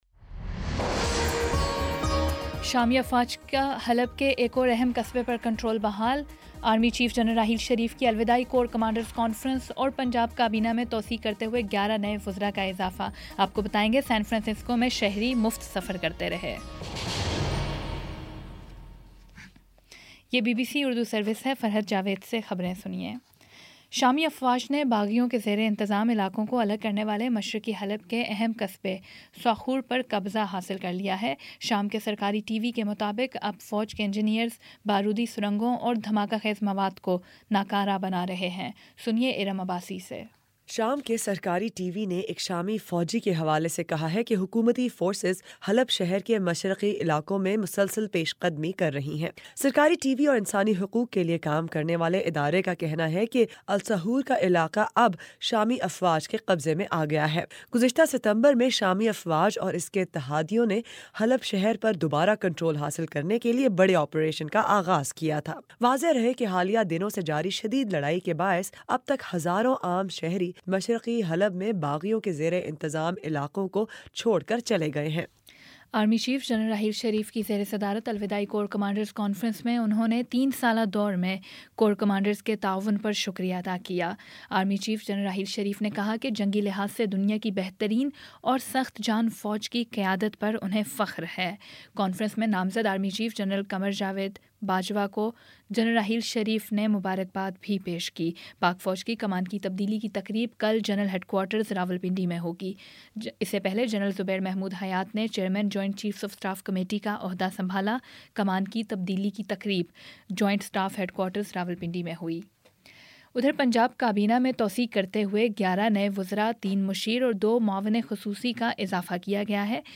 نومبر 28 : شام چھ بجے کا نیوز بُلیٹن